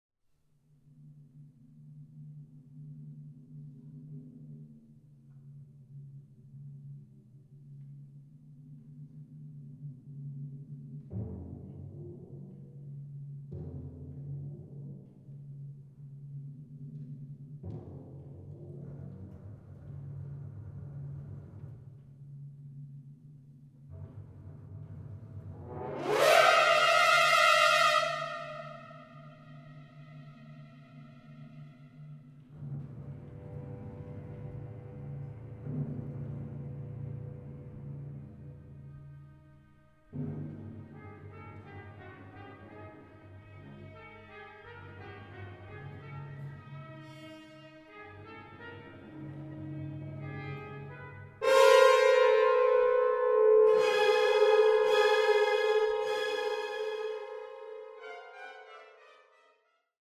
for Brass, Timpani and Strings